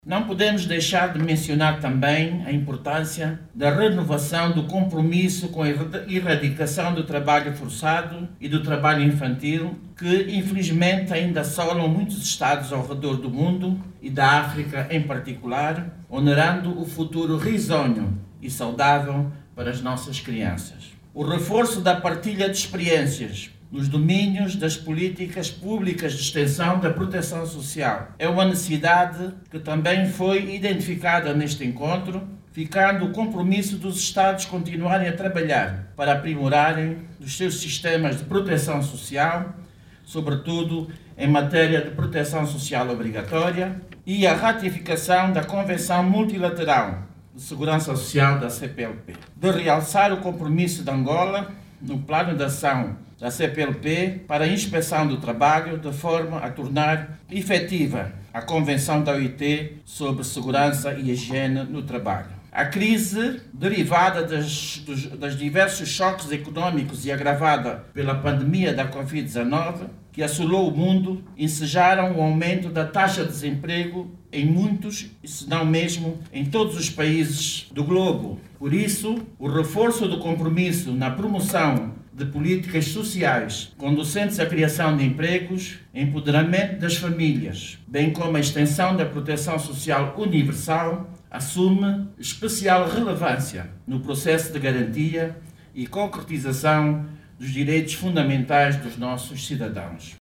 Oiça agora o aúdio da Ministra da Administração Pública, Trabalho e Segurança Social.
TERESA-DIAS-1.mp3